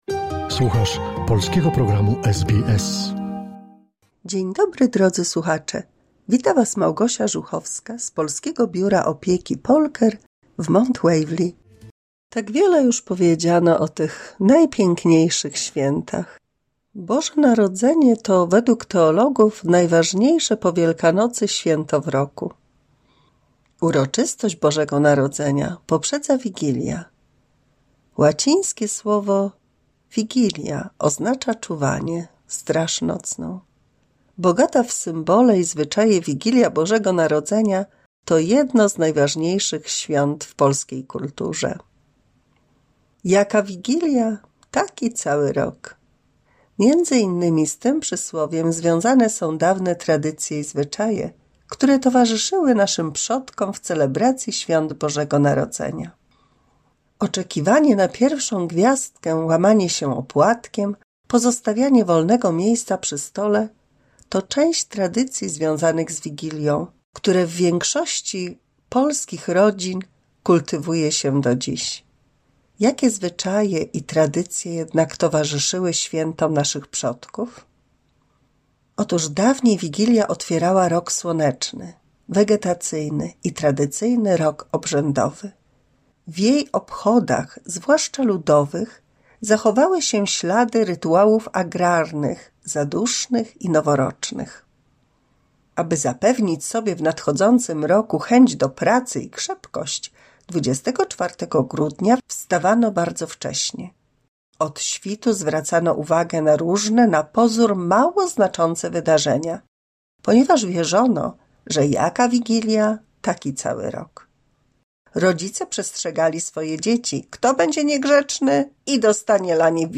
184 słuchowisko dla polskich seniorów